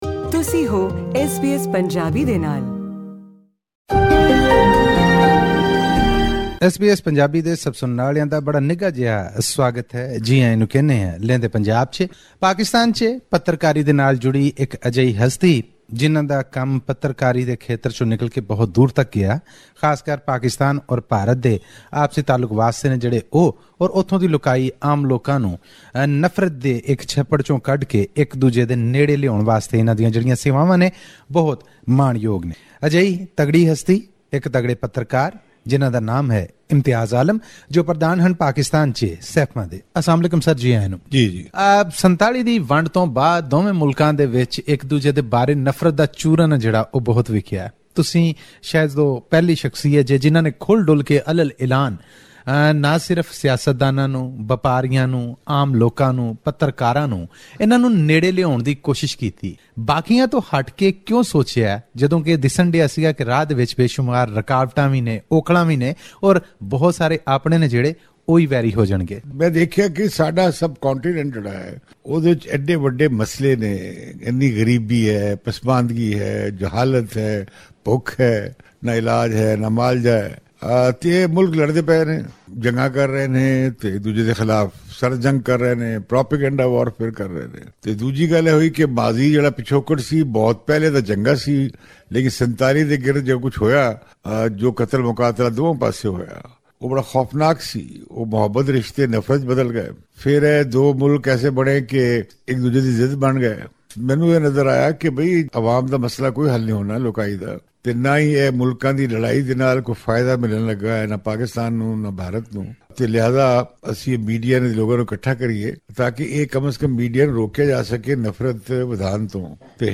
Click on the player in the picture above to listen to this interview in Punjabi.